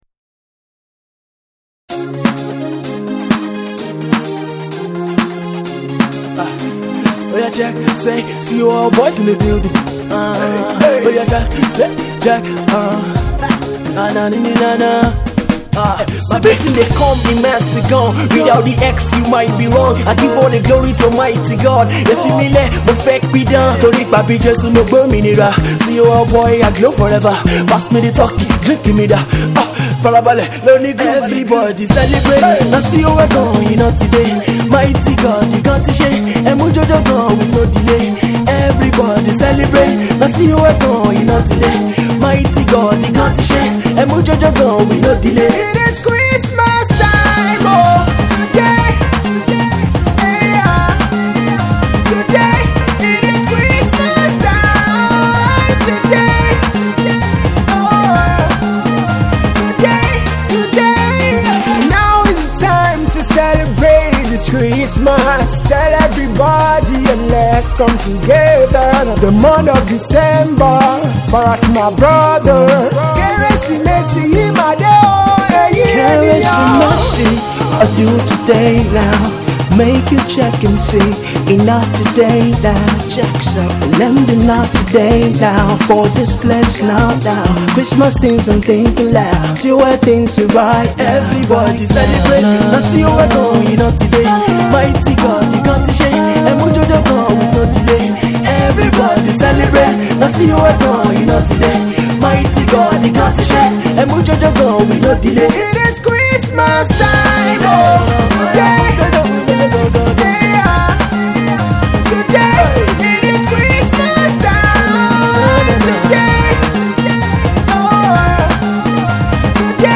a dance tune